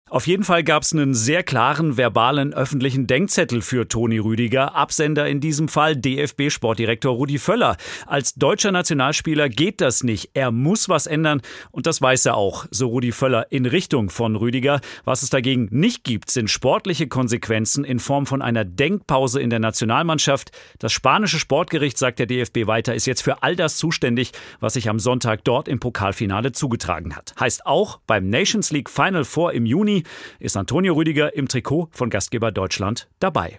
3. Nachrichten